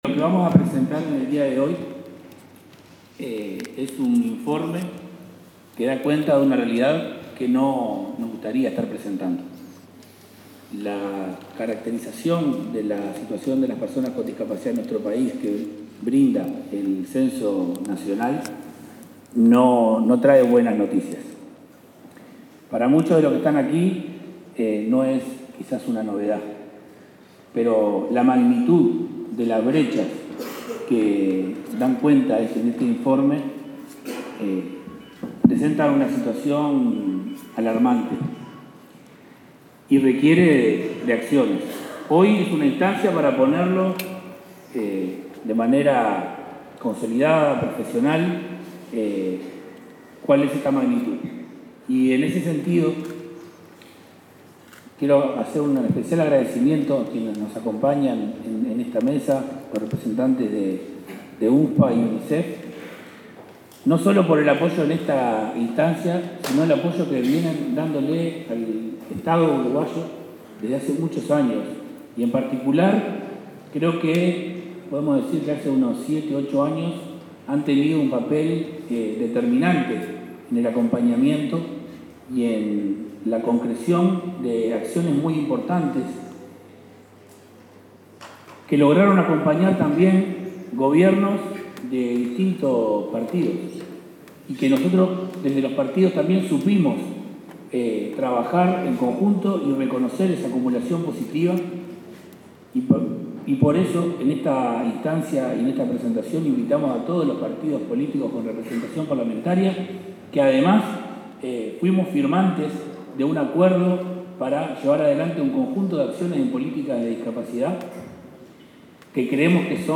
Palabras de autoridades en presentación de informe sobre discapacidad
El director del Área de Discapacidad del Ministerio de Desarrollo Social, Federico Lezama, y el titular de la cartera, Gonzalo Civila, expusieron en